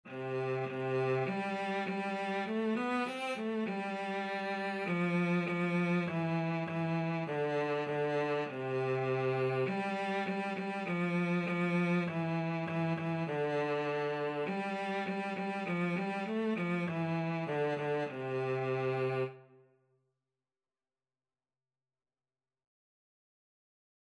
Childrens Childrens Cello Sheet Music Baa, Baa Black Sheep
C major (Sounding Pitch) (View more C major Music for Cello )
Moderato
4/4 (View more 4/4 Music)
Cello  (View more Beginners Cello Music)
Traditional (View more Traditional Cello Music)